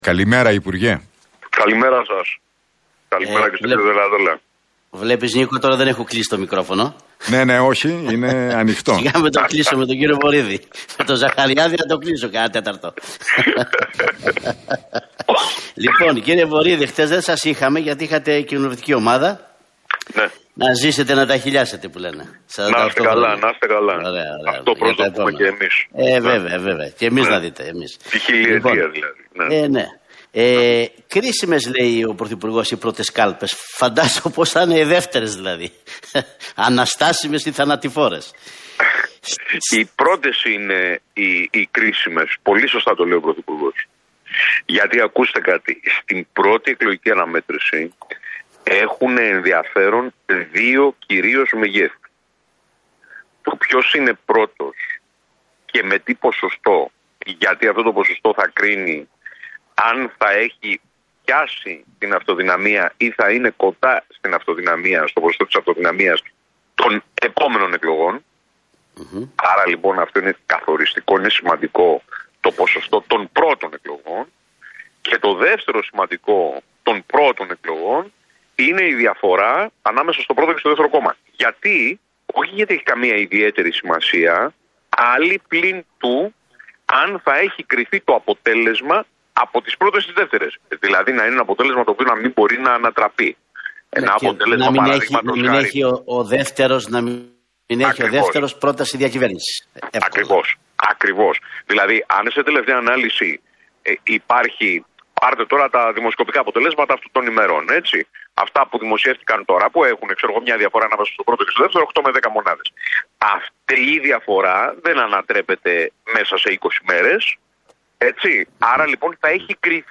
Ο υπουργός Εσωτερικών Μάκης Βορίδης, σε συνέντευξή του στον Realfm 97,8